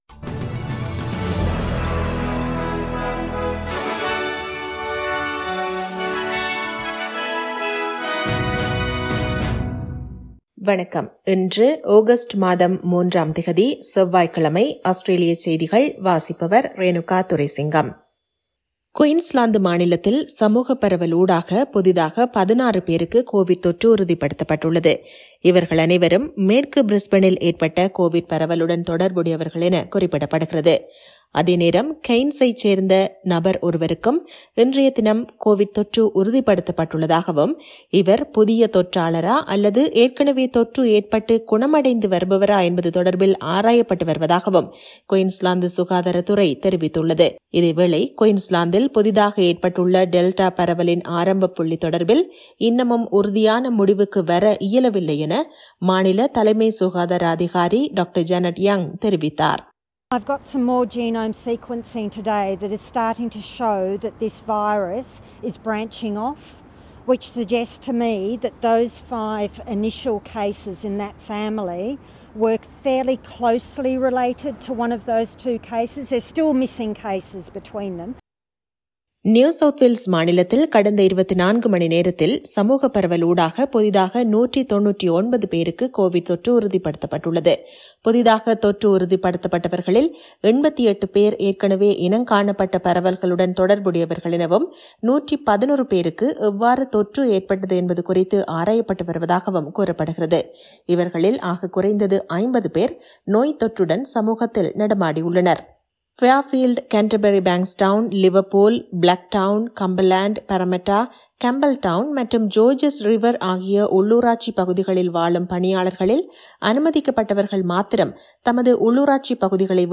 Australian news bulletin for Tuesday 03 Aug 2021.